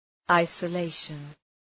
Προφορά
{,aısə’leıʃən}